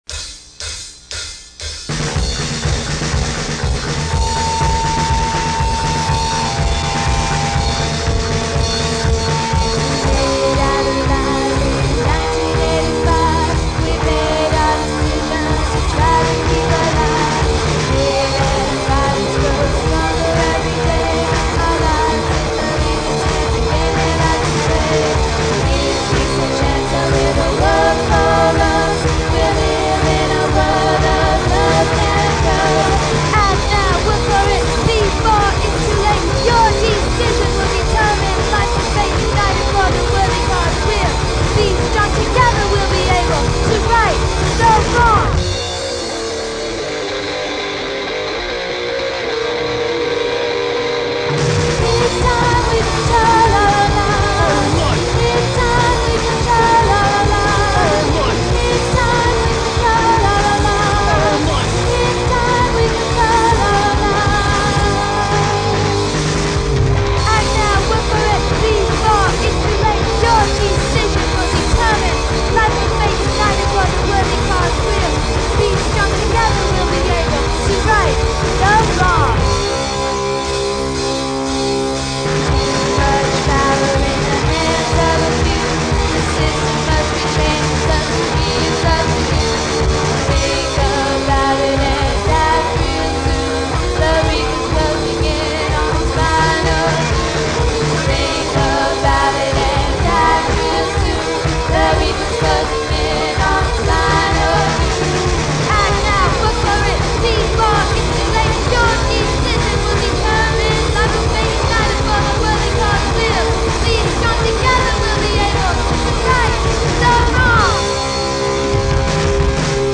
punk rock See all items with this value
Punk Rock Music